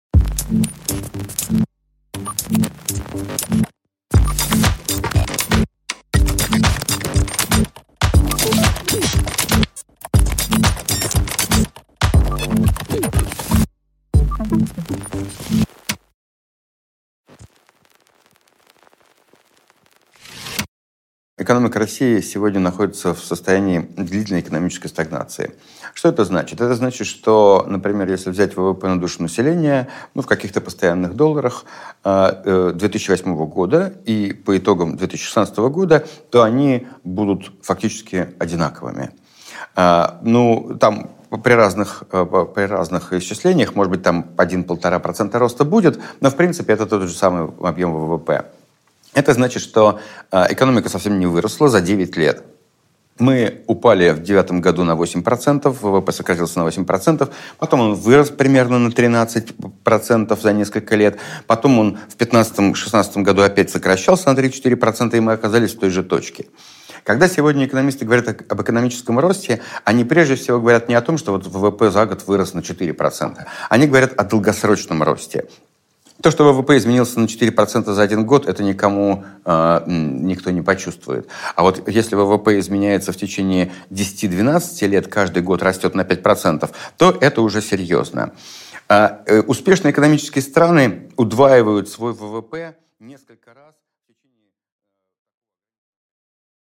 Аудиокнига Вперед, в СССР!